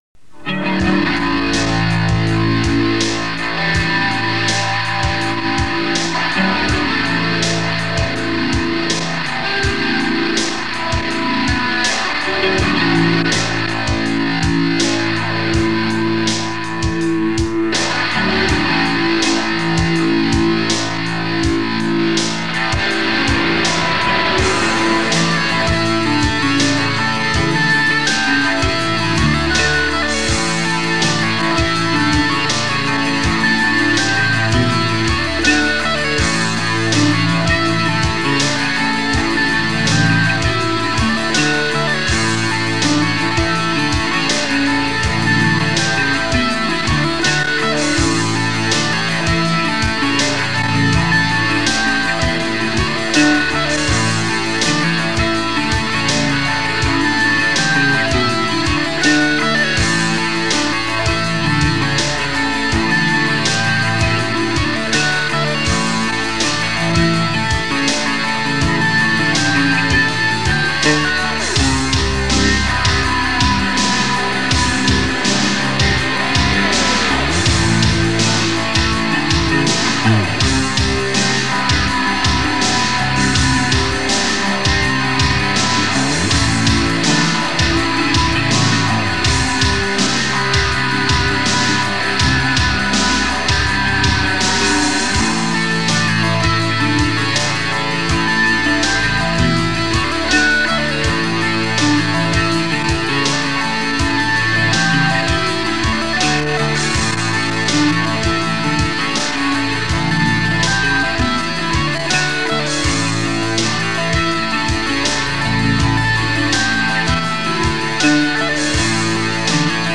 They didn't have a drummer, so they used a drum machine.
Very rough, early versions
with a home recorder and drum machine